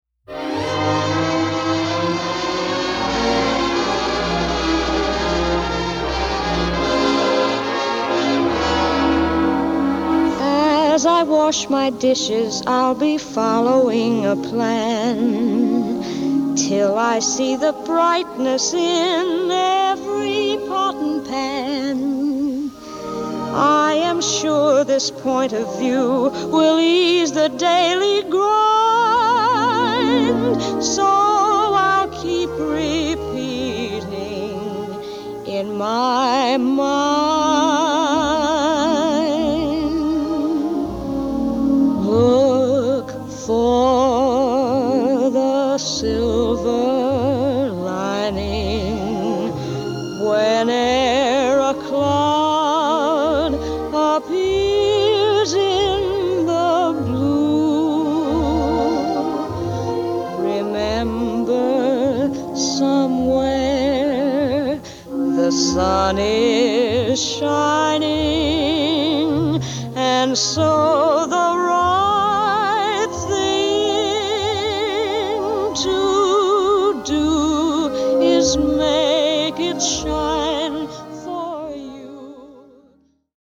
* Stereo Debut